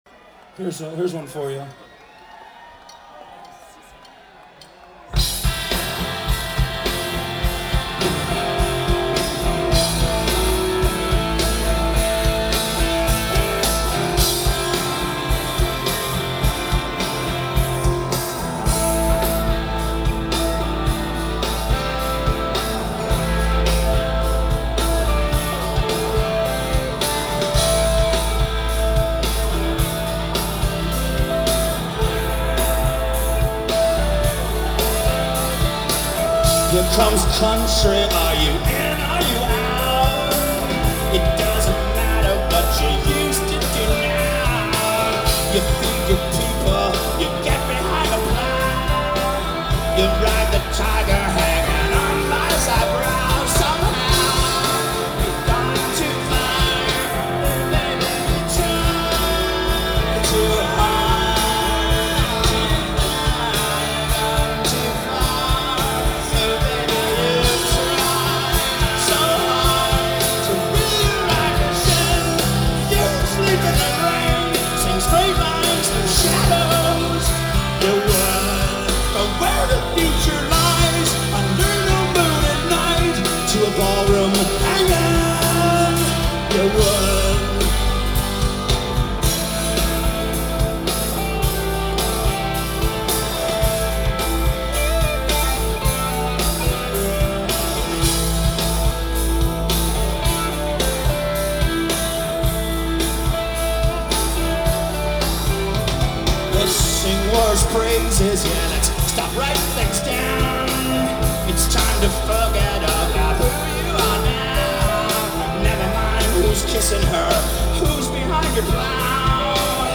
Live In 2003